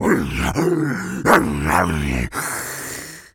tas_devil_cartoon_07.wav